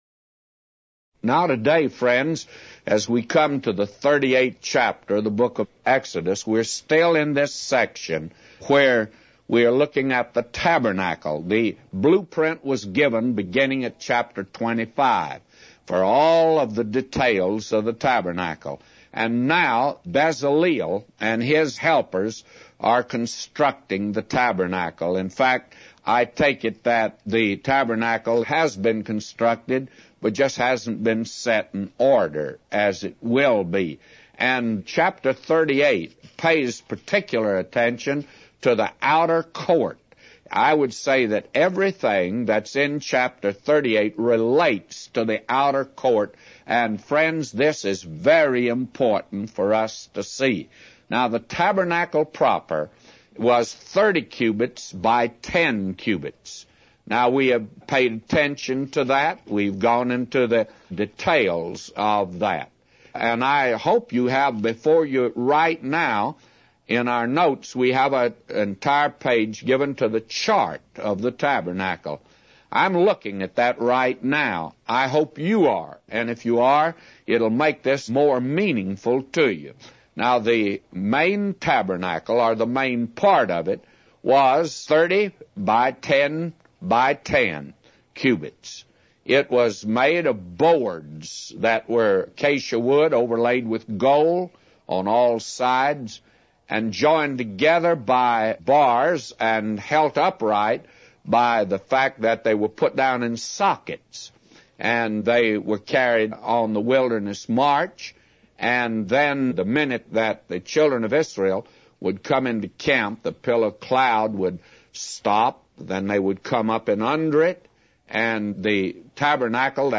A Commentary By J Vernon MCgee For Exodus 38:0-999